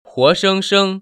[huóshēngshēng] 후어성성  ▶